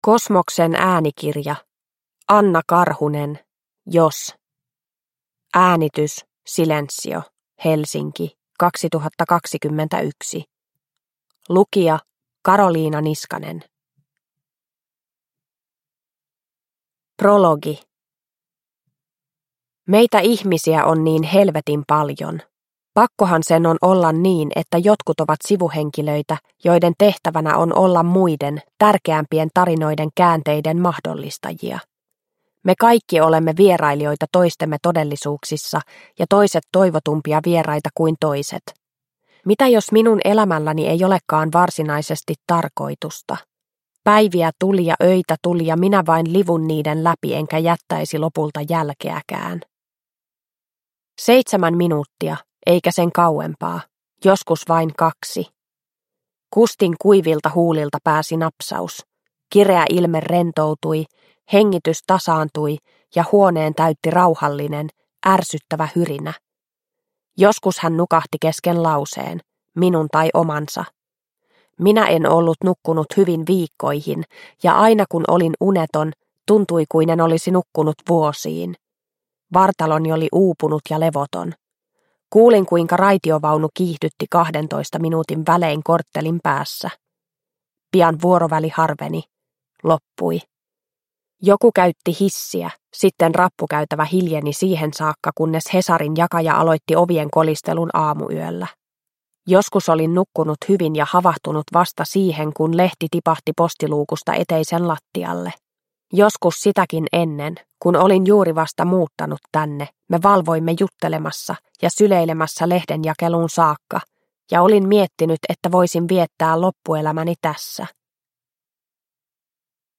Jos – Ljudbok – Laddas ner